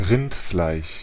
Ääntäminen
Synonyymit cowflesh fart complain Ääntäminen UK UK : IPA : /biːf/ Haettu sana löytyi näillä lähdekielillä: englanti Käännös Ääninäyte Substantiivit 1.